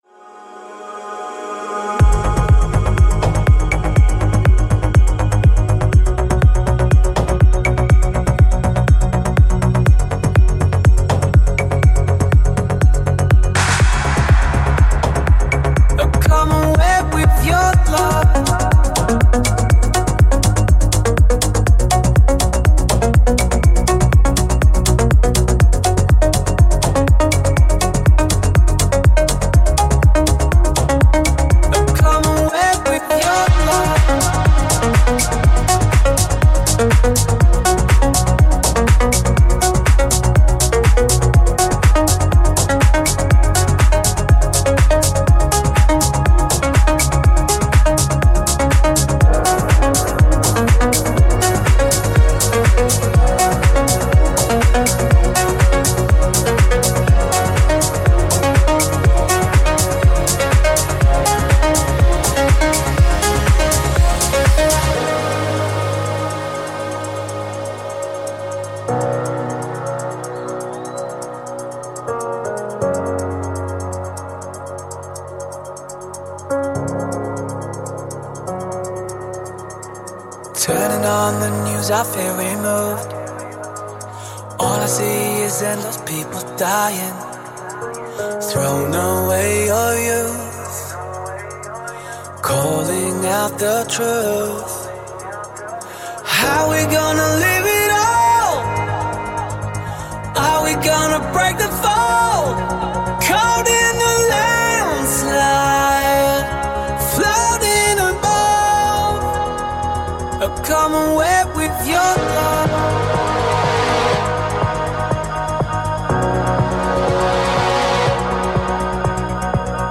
DJ Mixes and Radio Show